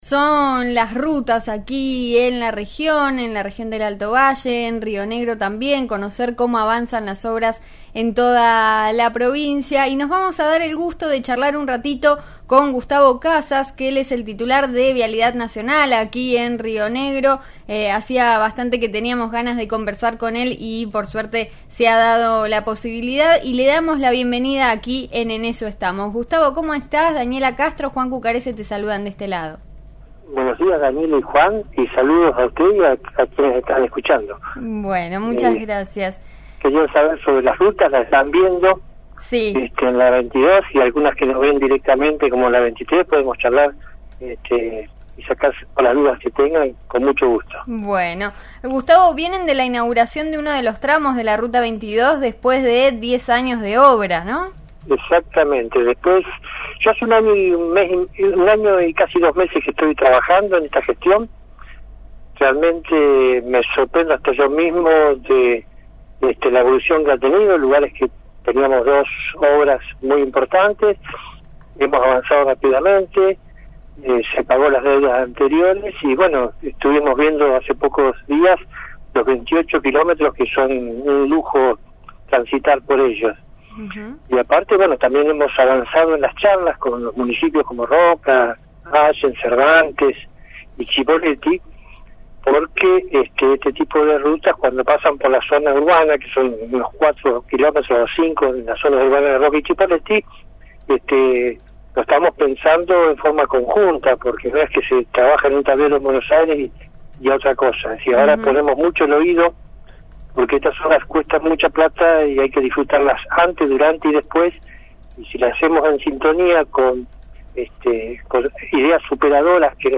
Gustavo Casas pasó por En Eso Estamos (RN RADIO 89.3) y aseguró que hay varias preocupaciones en los tramos de la región. Entre ellos, mencionó el paso de los camiones de arena hacia Vaca Muerta.